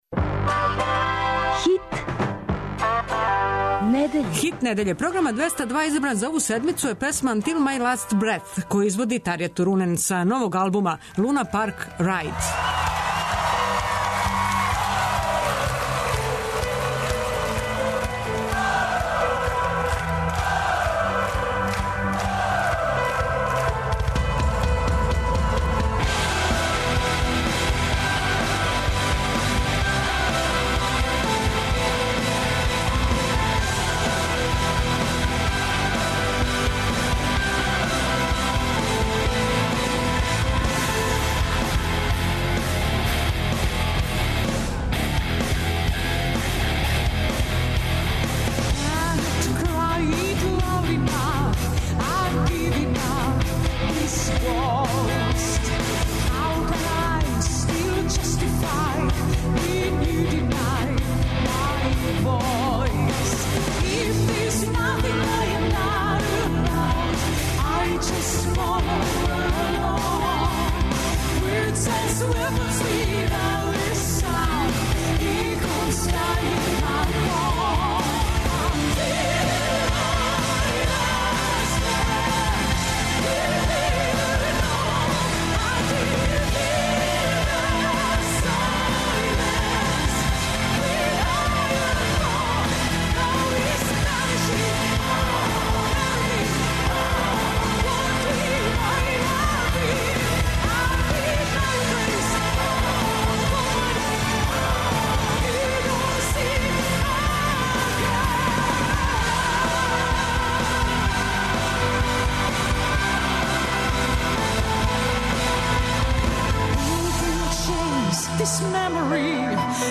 Слушаћемо старе хитове од 70-их до 90их, понеки стари хит 202 на данашњи дан, а подсетићемо се и понеке заборављене песме. У вечерњем термину, као и сваке среде слушамо актуелне хитове са стране рок и метал сцене.